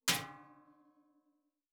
Metal Hit 1_3.wav